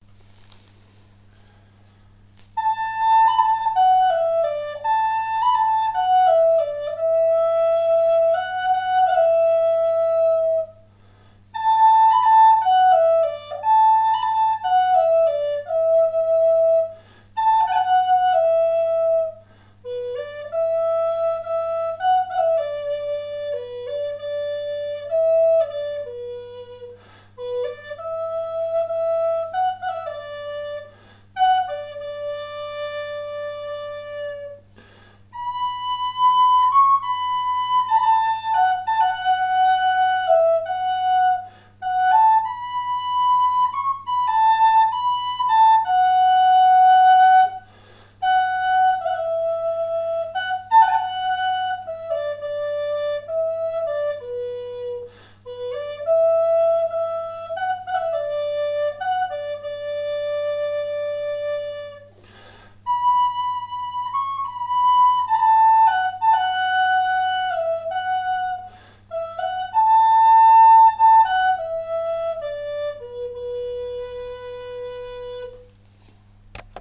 Flûte native Amérindienne en canne de Provence
Cette flûte Amérindienne en canne de Provence, joue une gamme pentatonique mineur de Si (en gardant bouché le 4 eme trous en partant du bas) et joue une gamme diatonique avec tous les trous.
Son-flute-amerindienne-si-roseau.mp3